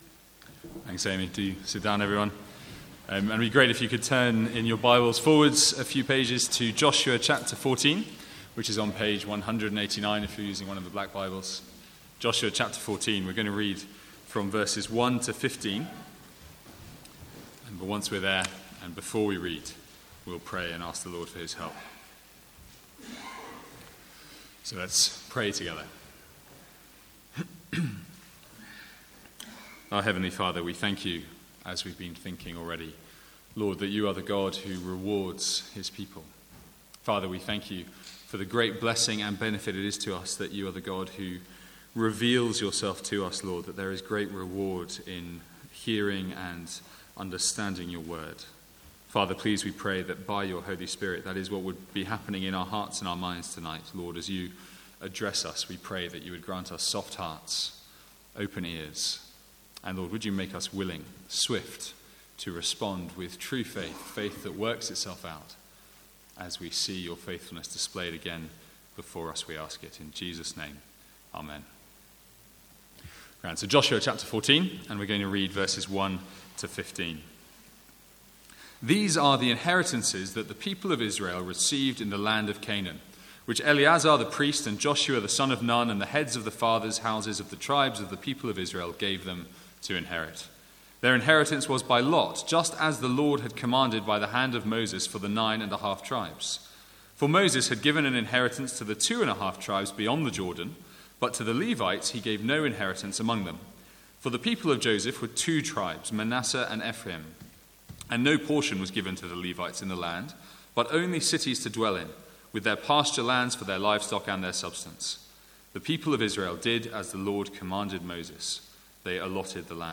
Sermons | St Andrews Free Church
From the Sunday evening series in Joshua.